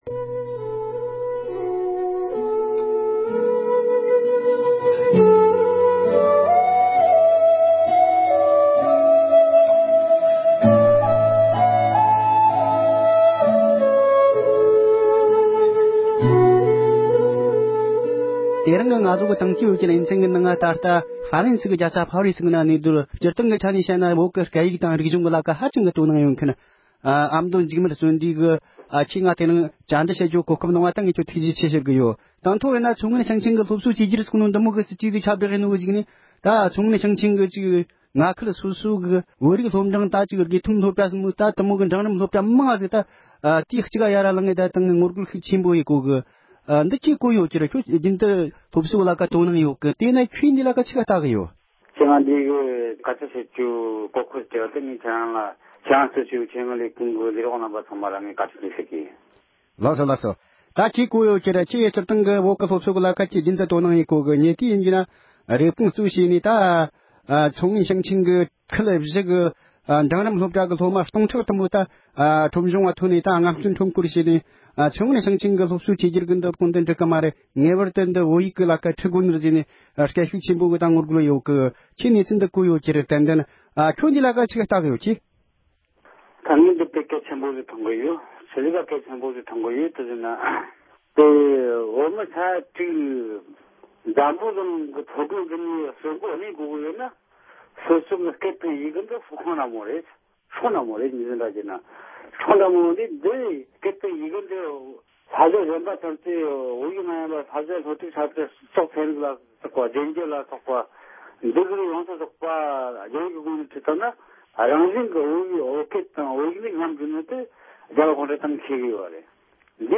རྒྱ་ནག་གཞུང་གིས་བོད་ནང་གི་ཆུང་འབྲིང་སློབ་གྲྭའི་སློབ་ཚན་བཅོས་བསྒྱུར་གྱི་སྲིད་ཇུས་བརྩམས་དགོས་པའི་དམིགས་ཡུལ་ཐད་གླེང་མོལ།